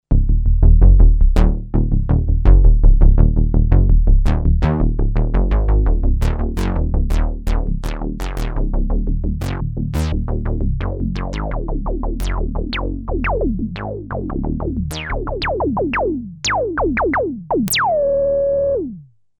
Pulse_bass: 3 oscillator bass, velocity controlling the envelope filter amount.
Unease_Pulse_bass.mp3